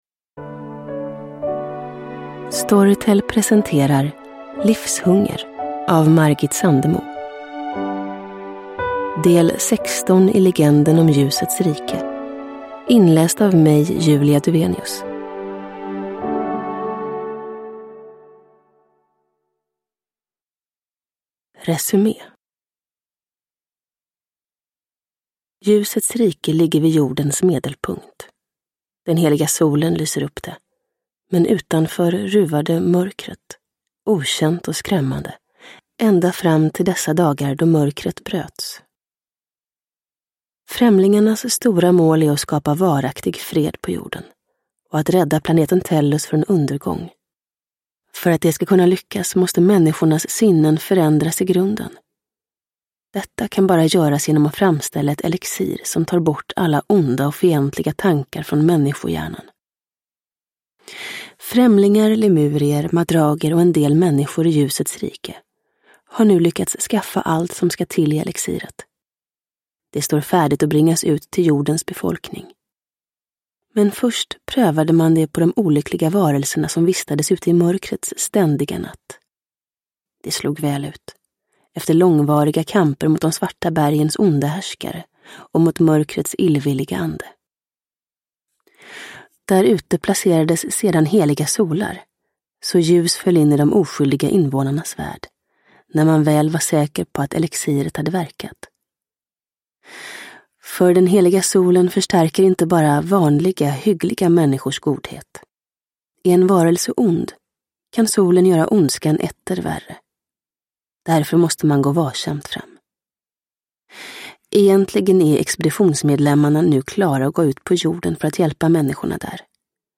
Livshunger – Ljudbok – Laddas ner